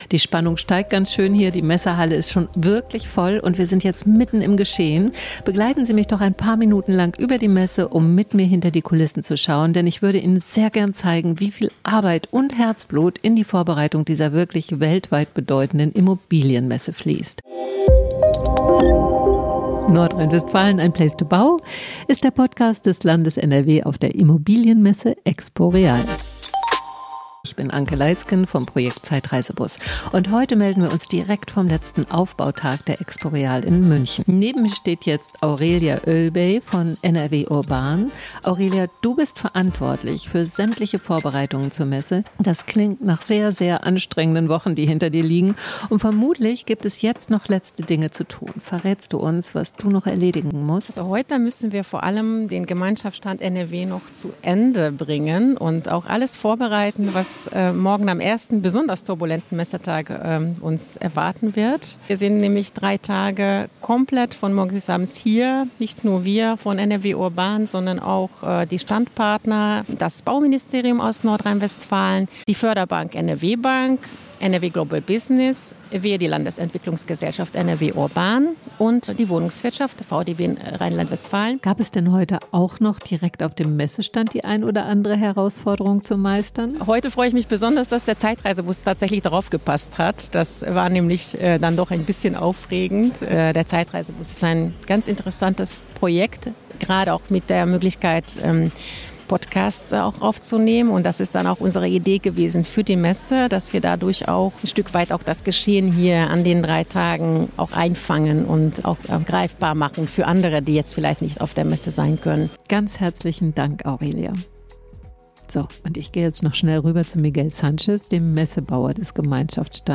NRW "place to bau" - Messe-Podcast auf der EXPO REAL 2024 – On Air aus dem Zeitreisebus